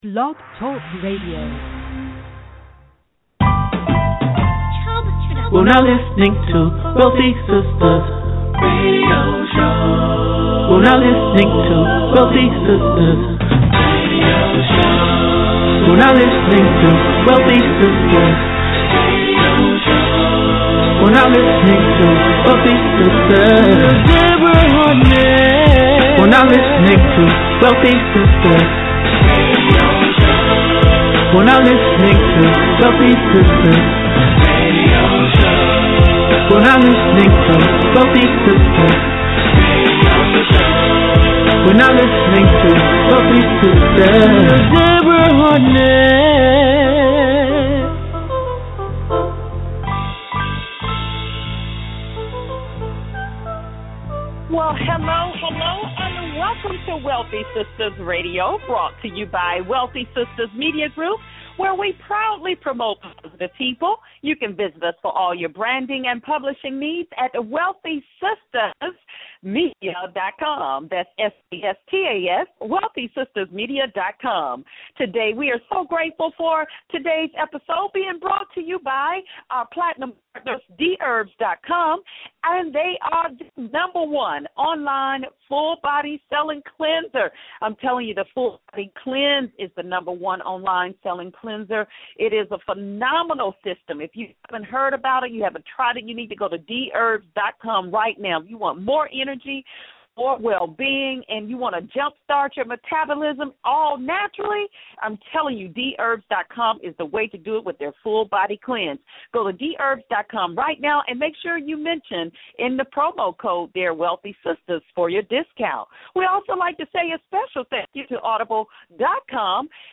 Wealthy Sistas® Radio Connecting Business with People, Stories, and Music LIVE Tuesdays 11 AM EST
And is intertwined with some of the greatest R&B music of all time.